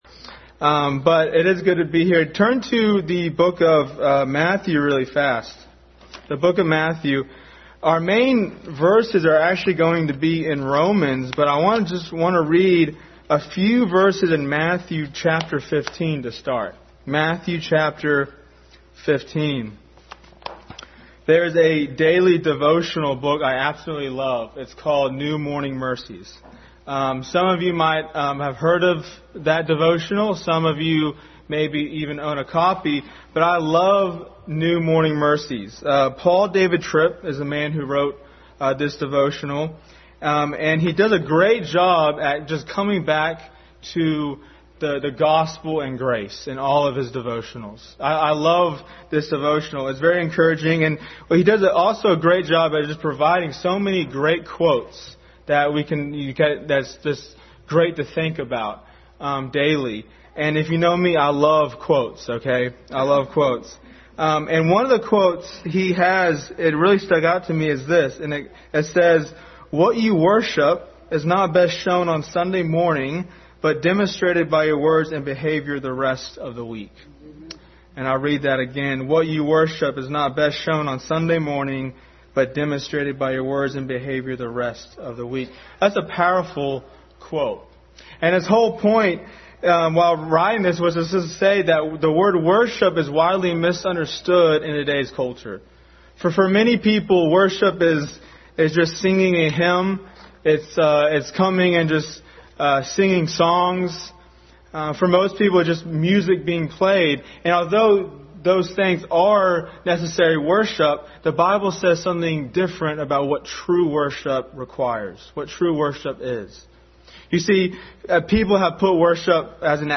True Worship Passage: Romans 12:1-2, Matthew 15:8-9, Revelation 1:3, Acts 17:1, Psalm 119:11, 1:2-3 Service Type: Family Bible Hour